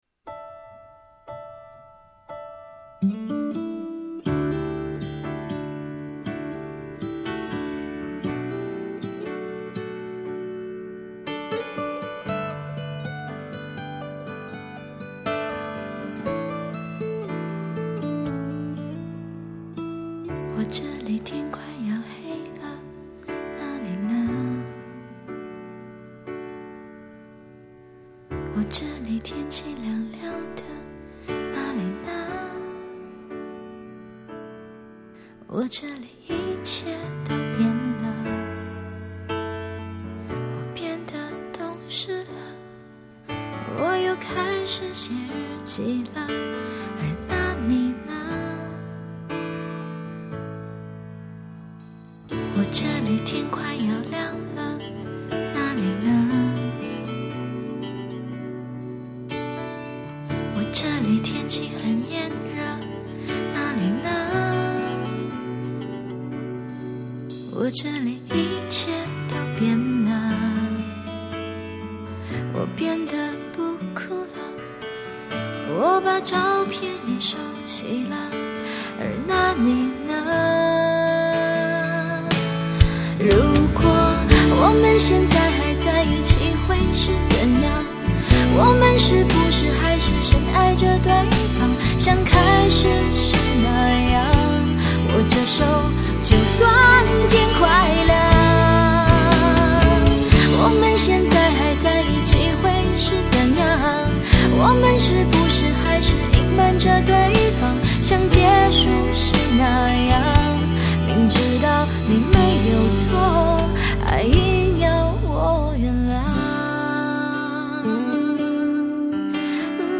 清脆的吉他前奏，安静的旋律，忧伤的词语